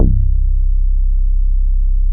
NERVOUS BASS.wav